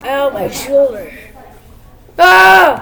Play, download and share ouch original sound button!!!!
ow-my-shoulder-ahh_UyQ6HxZ.mp3